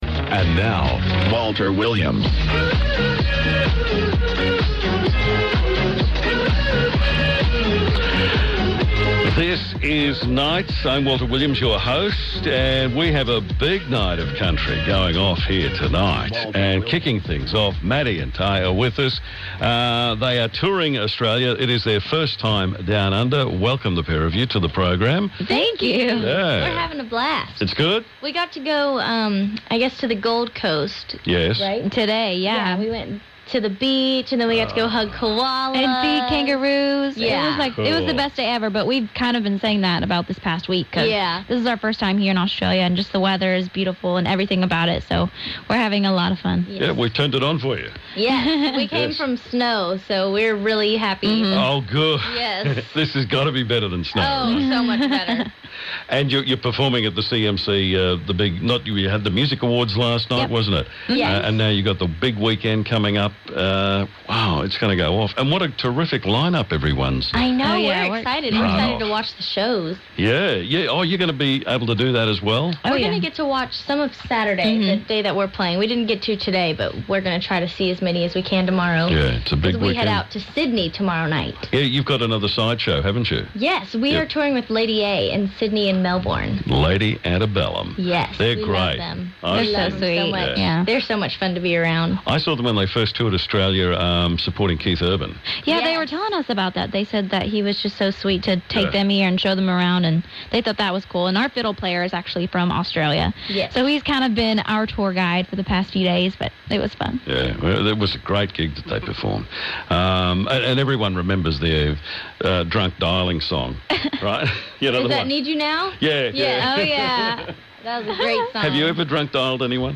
country music festival, US Country Music duo
in the studio
performed an acoustic version of their hit song